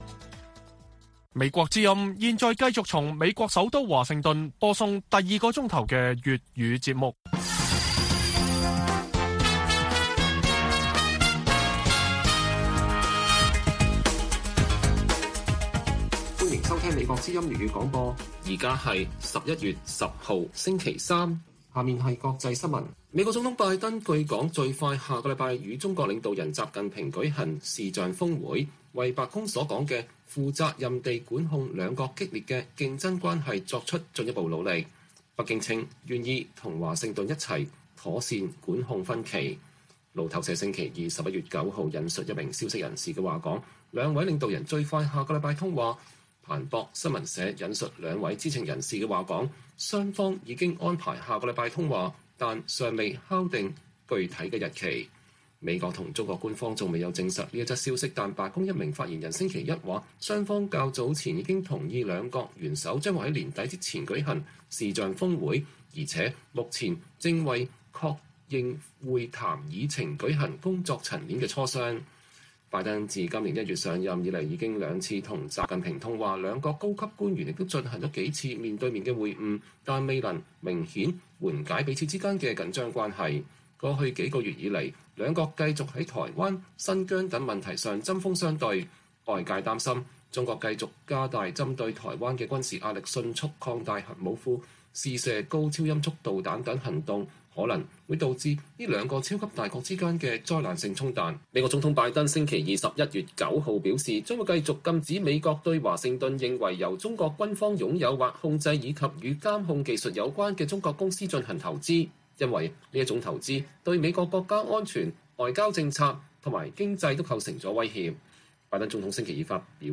粵語新聞 晚上10-11點: 拜登宣佈繼續禁止美國人投資中國的軍工和與監控技術有關的企業